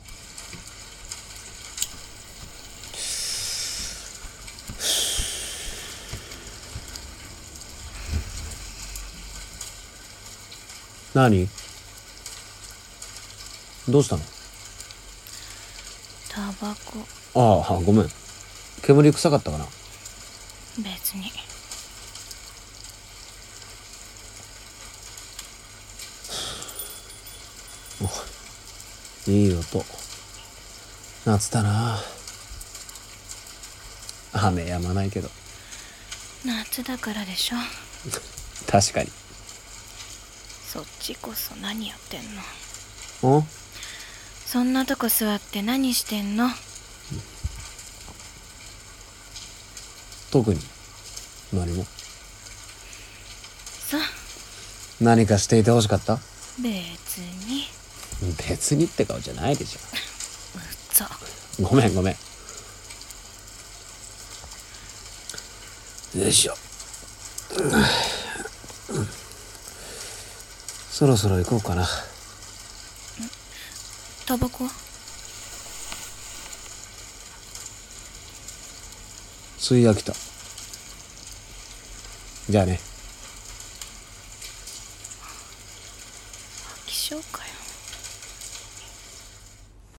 【声劇】多情